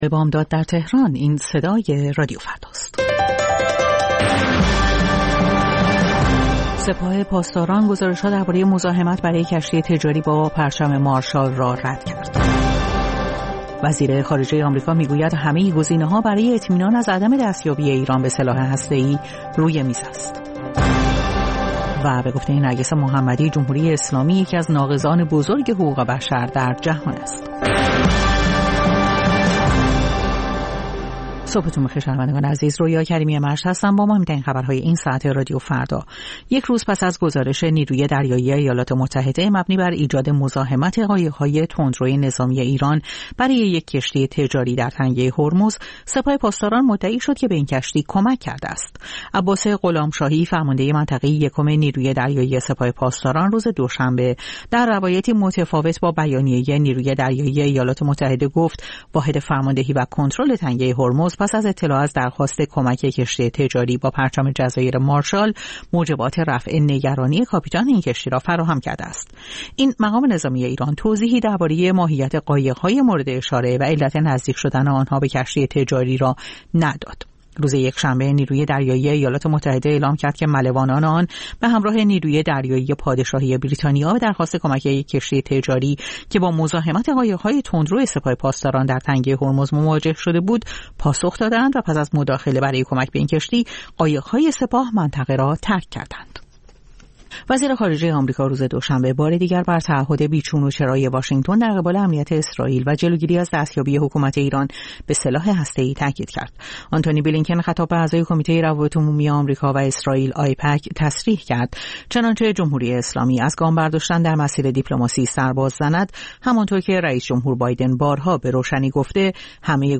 سرخط خبرها ۲:۰۰